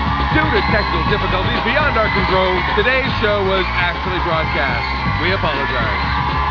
This is the oddest (and funniest) voice-over I've ever heard regarding '